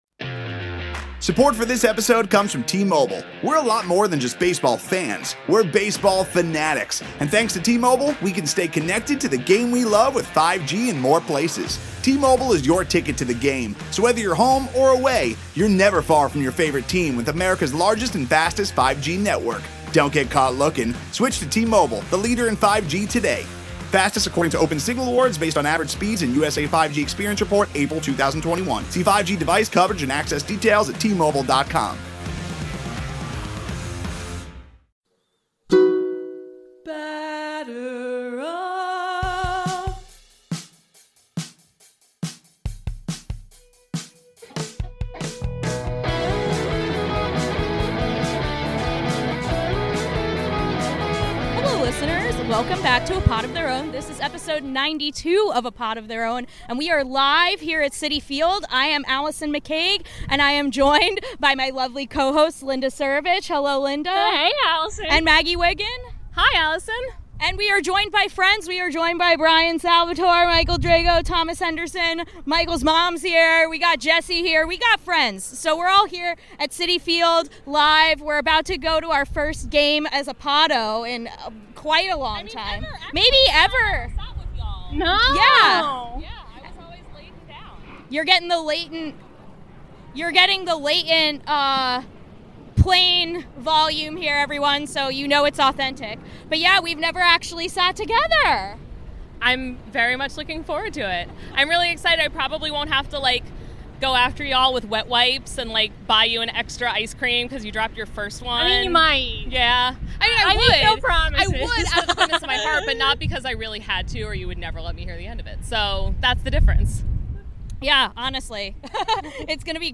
Live from the Citi Field parking lot
Welcome back to A Pod of Their Own, a show by the women of Amazin’ Avenue where we talk all things Mets, social justice issues in baseball, and normalize female voices in the sports podcasting space.
We reunited, live and in person, in the Citi Field parking lot before Monday’s game between the Mets and the Brewers to discuss Tylor Megill, the future of the Mets’ rotation, potential bullpen ace Noah Syndergaard, All-Star snubs, and more.
Enjoy the lovely authentic ambiance of a parking lot tailgate complete with the sounds of planes flying overhead and greetings from passersby. We wrap the show up as we always do with Walk-off Wins, where each of us talks about what’s making us happy this week, baseball-related or otherwise.